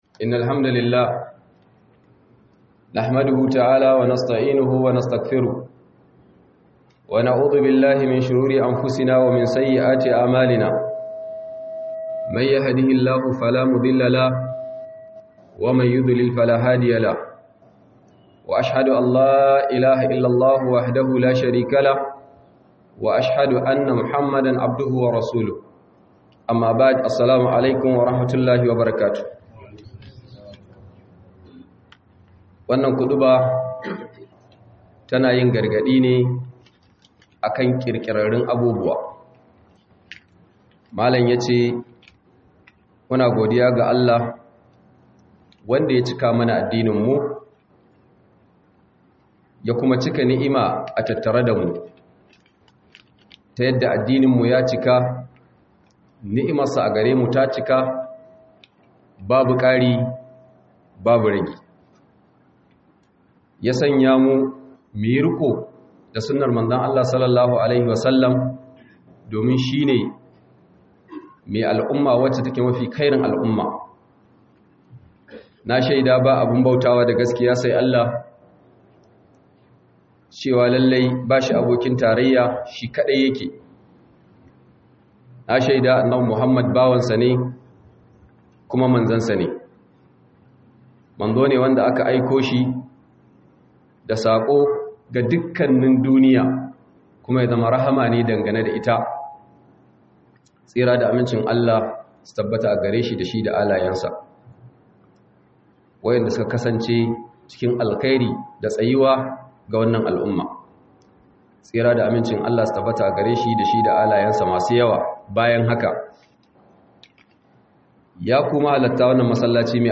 Khuduba
Khudubar Sallar Juma'a